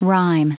Prononciation du mot rime en anglais (fichier audio)
Prononciation du mot : rime